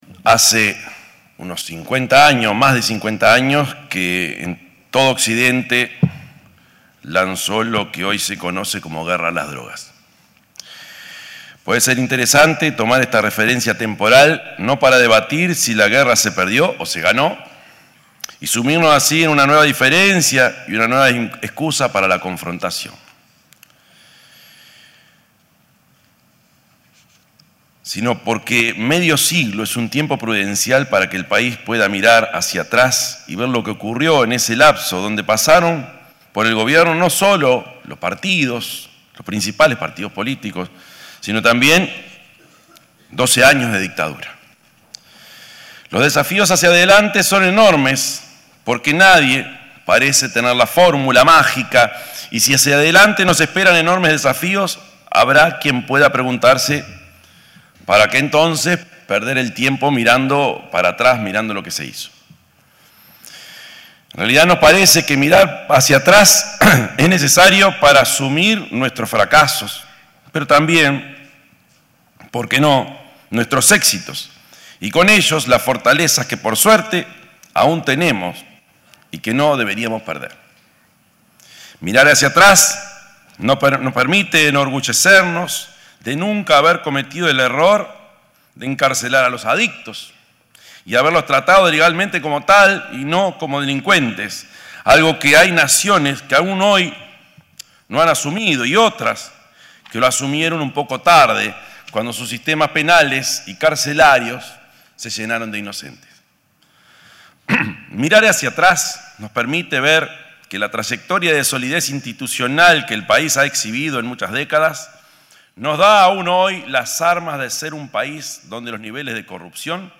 Palabras del presidente de la República, Yamandú Orsi
Palabras del presidente de la República, Yamandú Orsi 04/08/2025 Compartir Facebook X Copiar enlace WhatsApp LinkedIn El presidente de la República, Yamandú Orsi, se expresó en el seminario El Narco nos Jaquea. Una Espada de Damocles sobre las Democracias, que se desarrolló en el hotel Radisson, organizado por Yunta Uy.